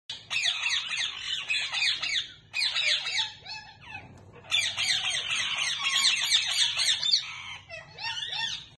Love Bird parrots 🦜 sound effects free download